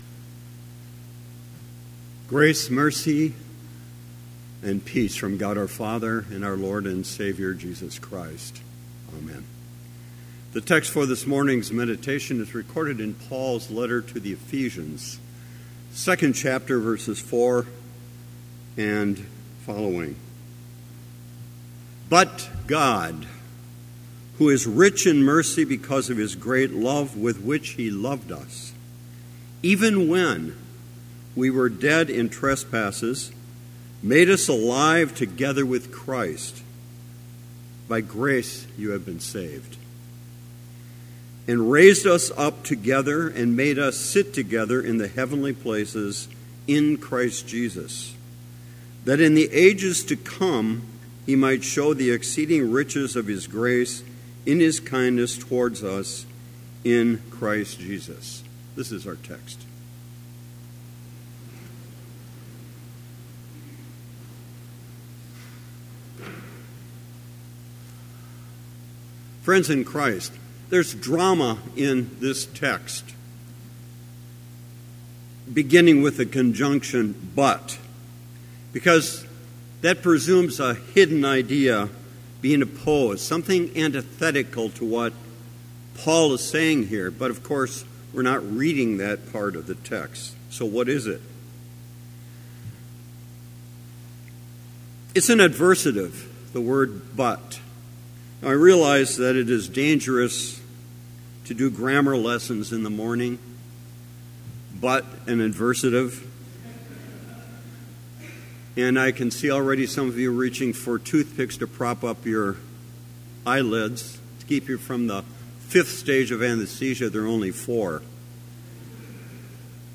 Complete service audio for Chapel - January 30, 2018